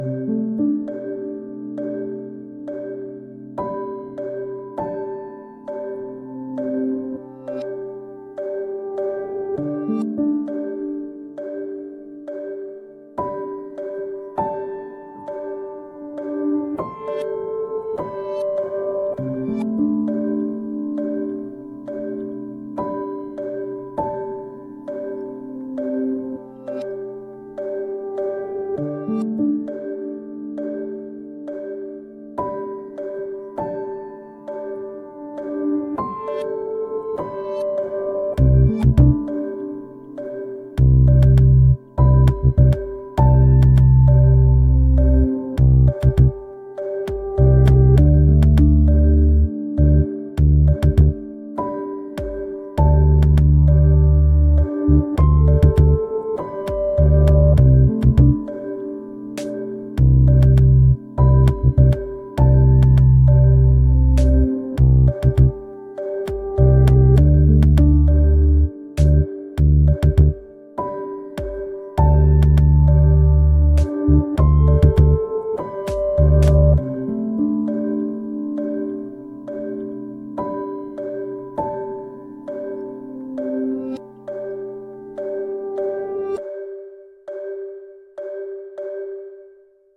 水と踊る (一人声劇／朗読)